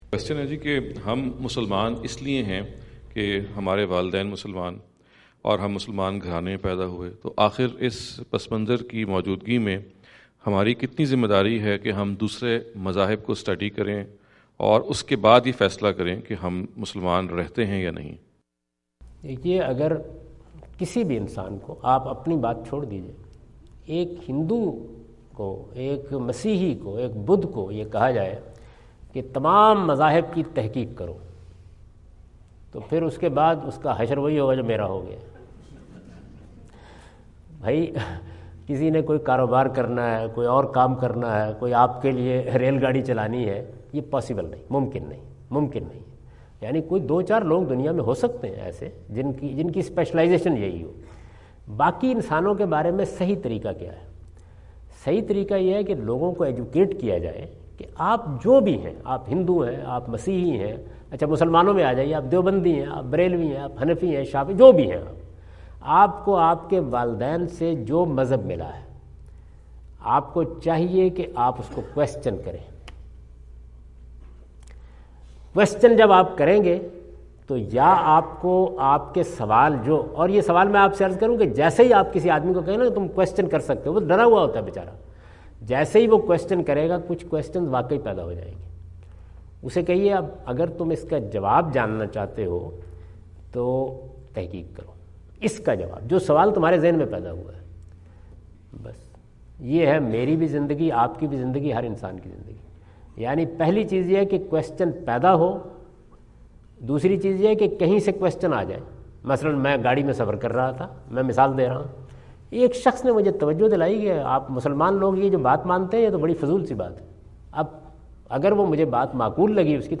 Javed Ahmad Ghamidi answer the question about "Responsibility of Born Muslims Regarding Religion" asked at Aapna Event Hall, Orlando, Florida on October 14, 2017.
جاوید احمد غامدی اپنے دورہ امریکہ 2017 کے دوران آرلینڈو (فلوریڈا) میں "مسلمان گھرانے میں پیدا ہونے والوں کی مذہبی ذمہ داری" سے متعلق ایک سوال کا جواب دے رہے ہیں۔